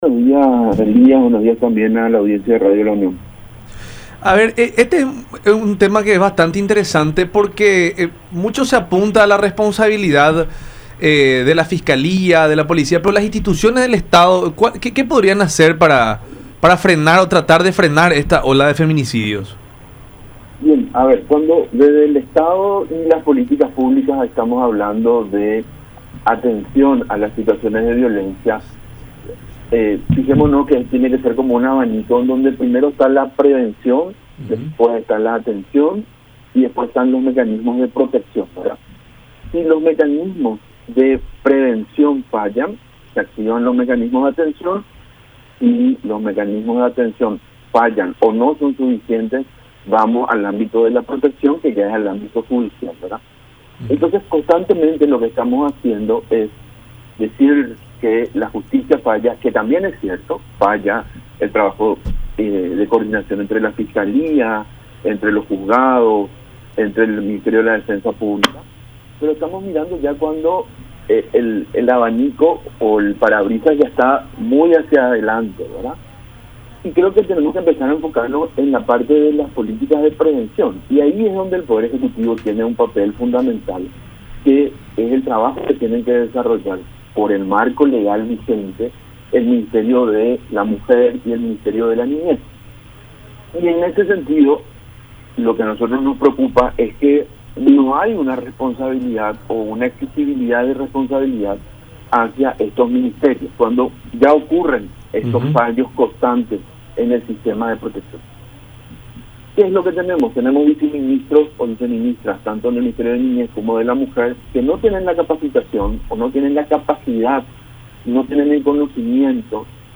en diálogo con La Unión Hace La Fuerza a través de Unión TV y radio La Unión.